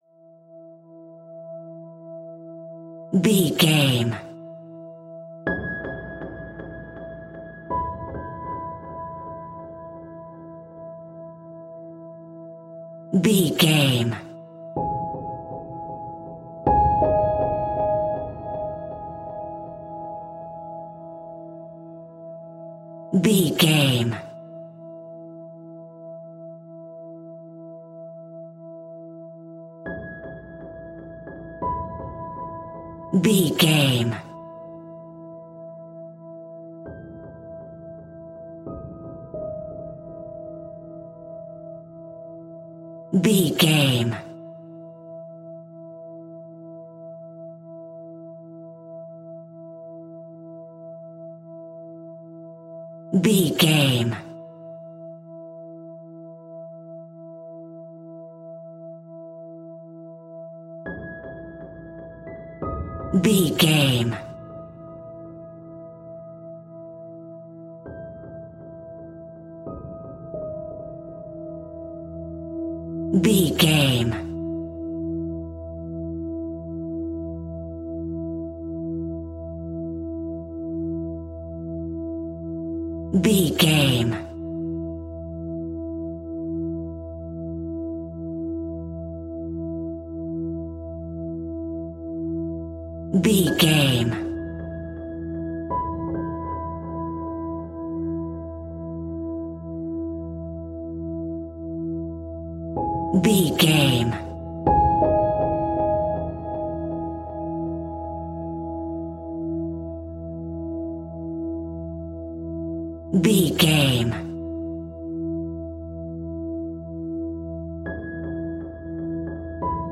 Scary Dark Piano Drone.
In-crescendo
Aeolian/Minor
ominous
eerie
horror music
horror piano